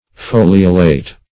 Foliolate \Fo"li*o*late\, a.